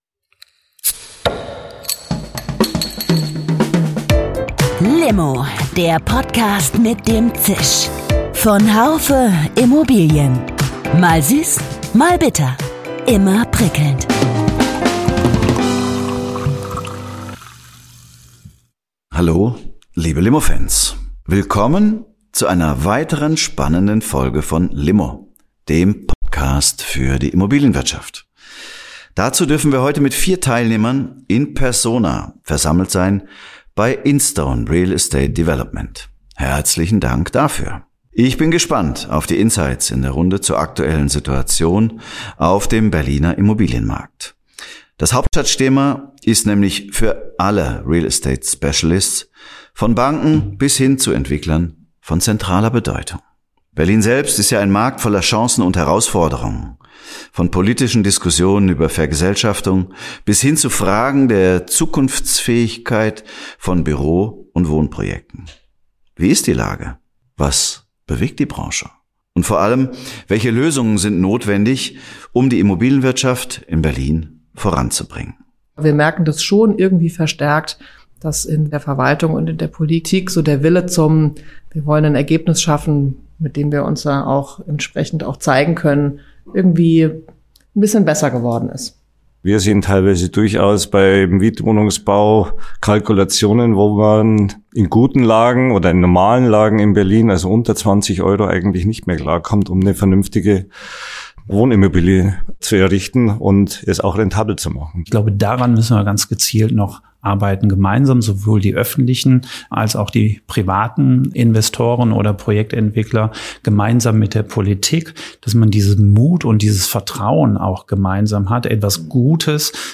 Vier Teilnehmer sind in persona versammelt bei Instone Real Estate Development in Berlin.